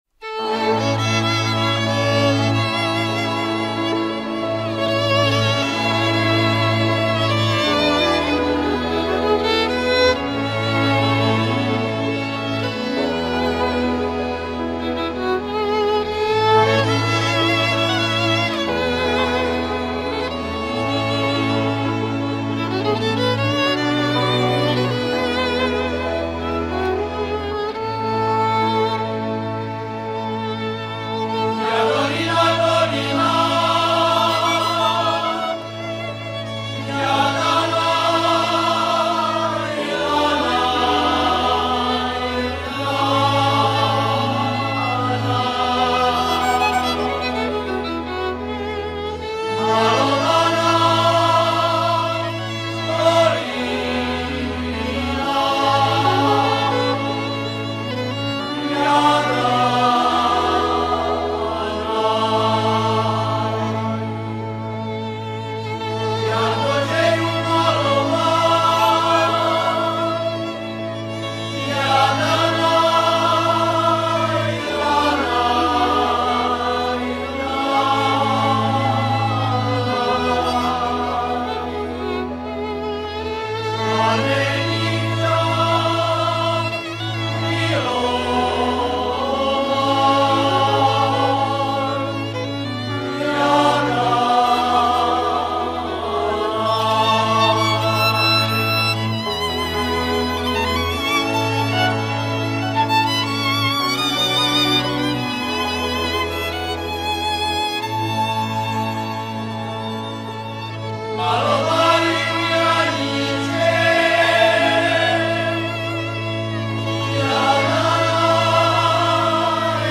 1. hlas zvuková nahrávka
Kategorie: Rhytm&Blues